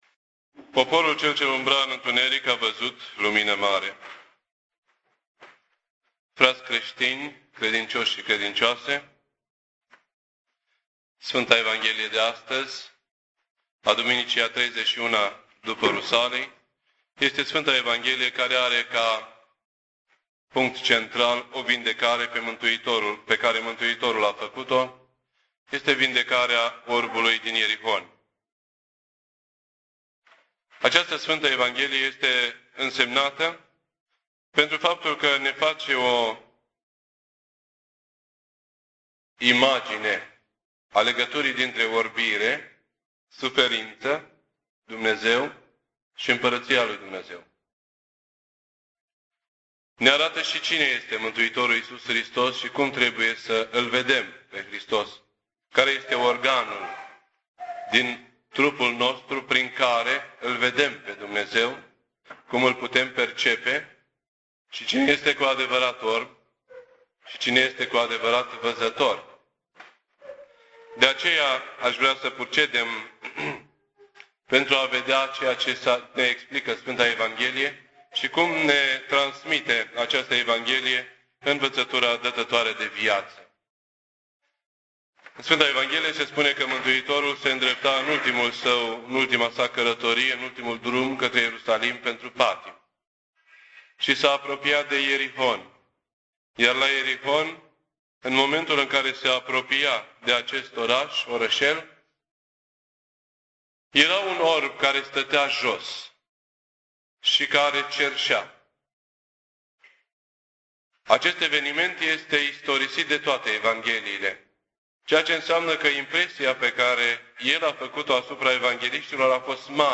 This entry was posted on Sunday, December 2nd, 2007 at 10:01 AM and is filed under Predici ortodoxe in format audio.